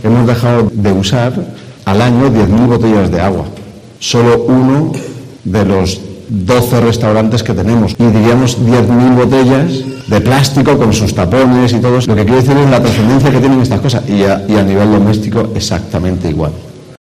Quique Dacosta, embajador de Tuawa, explica la reducción de plástico lograda en uno de sus locales